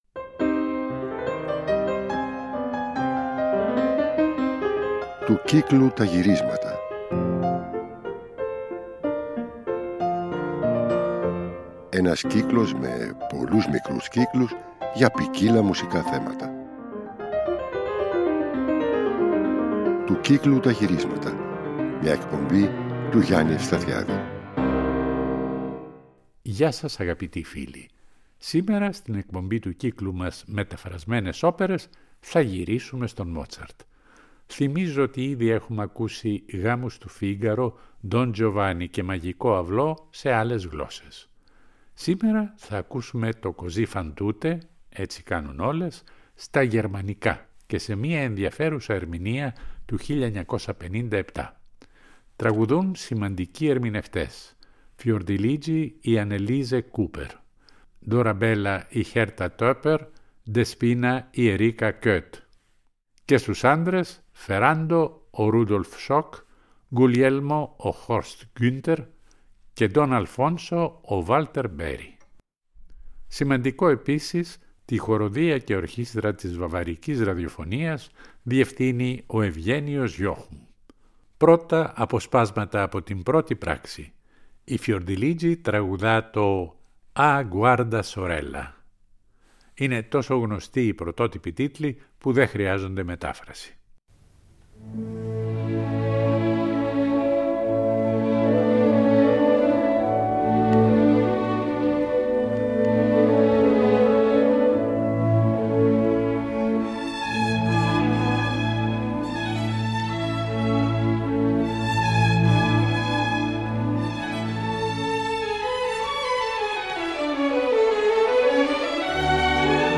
σε μια ιστορική ερμηνεία του 1957